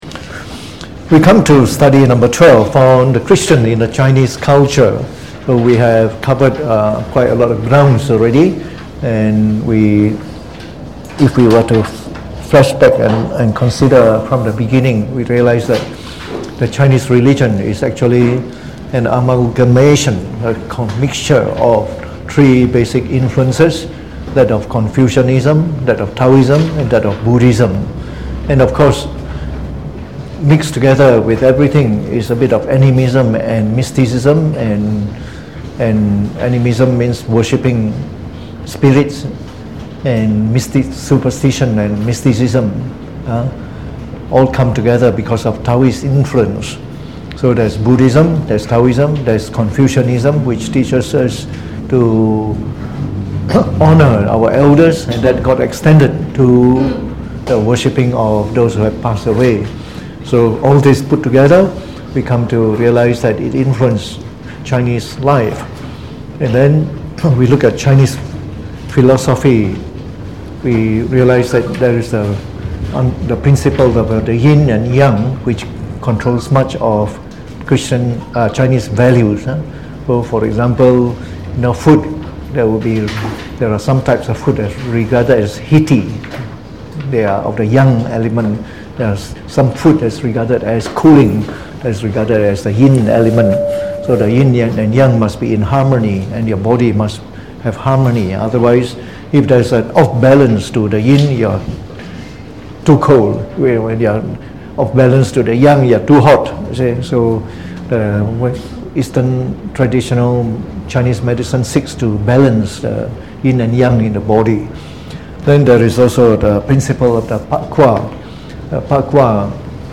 Delivered on the 20th of Nov 2019 during the Bible Study, from the series on The Chinese Religion.